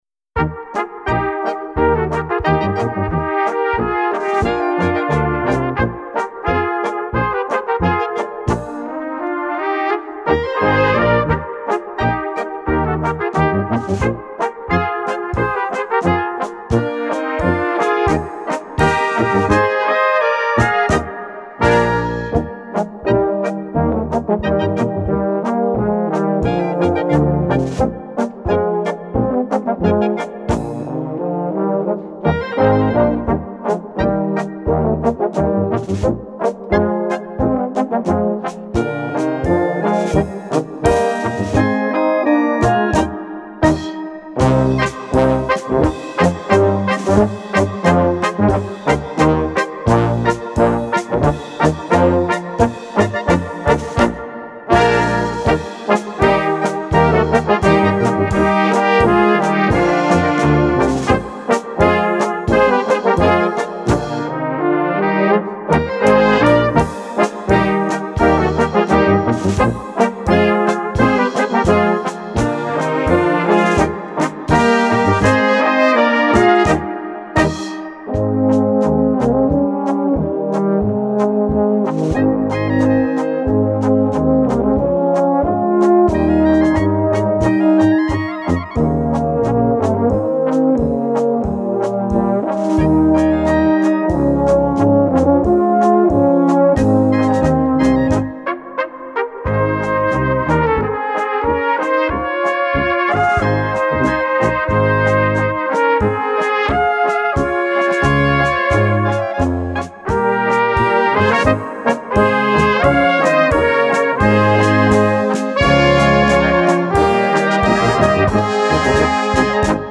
Gattung: Polka
Besetzung: Blasorchester
Ein leicht spielbare und ins Ohr gehende Polka.